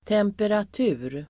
Ladda ner uttalet
Uttal: [temperat'u:r]